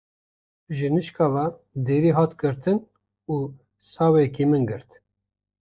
/ɡɪɾˈtɪn/